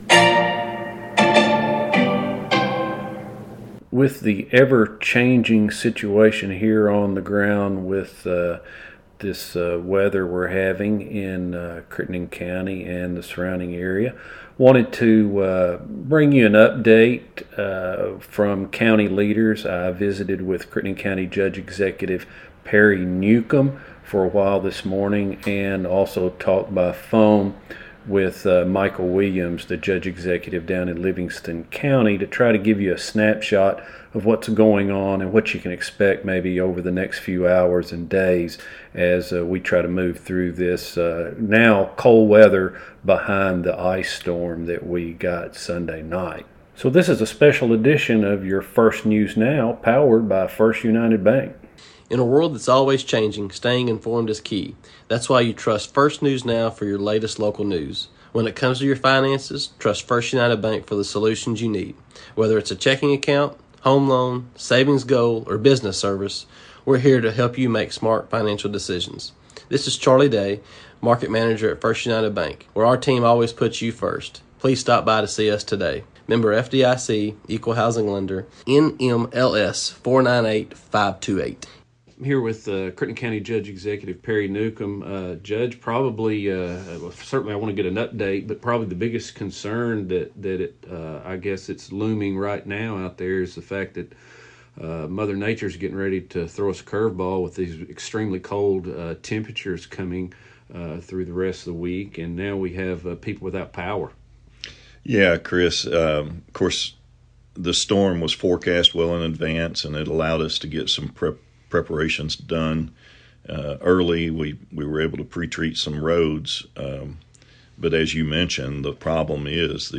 BONUS NEWScast with Judge Newcom & Judge Williams
BONUS NEWScast with Judge Newcom & Judge Williams SPECIAL REPORT | STORM INFORMATION This is an additional newscast for M onday with local leaders from Crittenden and Livingston counties LISTEN NOW By Crittenden Press Online at January 06, 2025 Email This BlogThis!